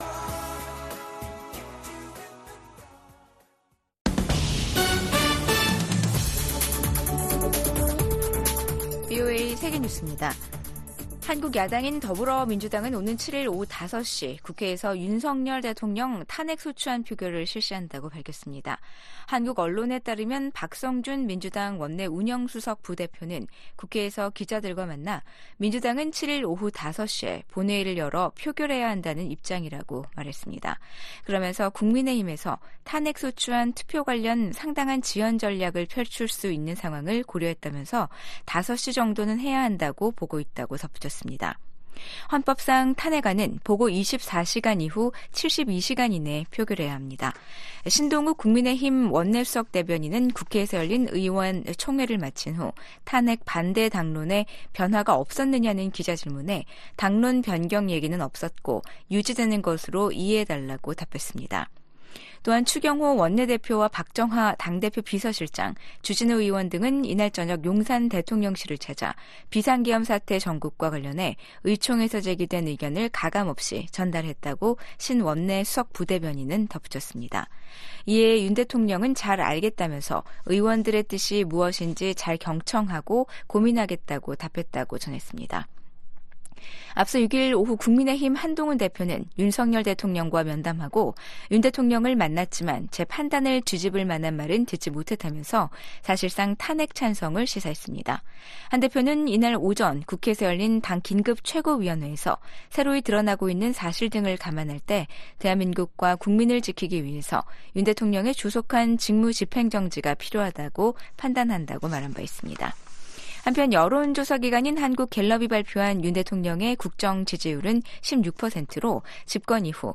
VOA 한국어 아침 뉴스 프로그램 '워싱턴 뉴스 광장'입니다. 윤석열 대통령 탄핵소추안에 대한 국회 표결을 하루 앞두고 한국 내 정국은 최고조의 긴장으로 치닫고 있습니다. 미국 국무부는 한국이 대통령 탄핵 절차에 돌입한 것과 관련해 한국의 법치와 민주주의를 계속 지지할 것이라고 밝혔습니다. 한국의 계엄 사태와 관련해 주한미군 태세에는 변함이 없다고 미국 국방부가 강조했습니다.